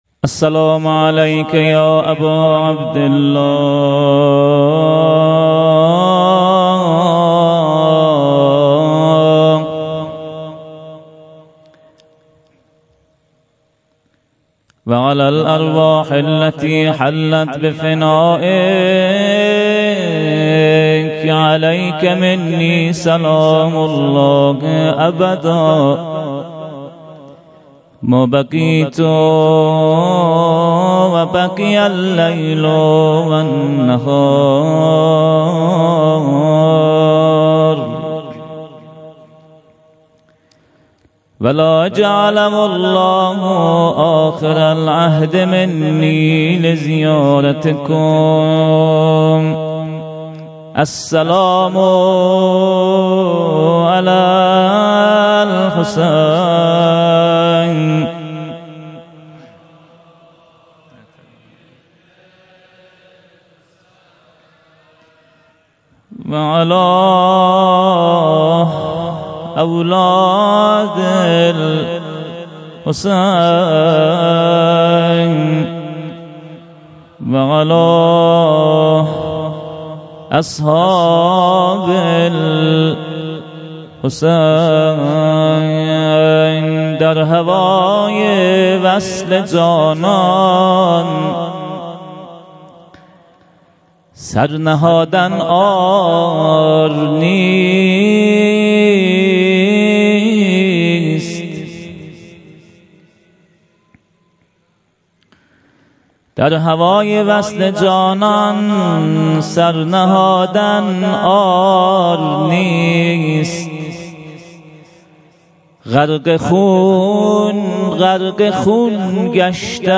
شب هشتم- مقدمه و روضه
هیأت محبان اهل بیت علیهم السلام چایپاره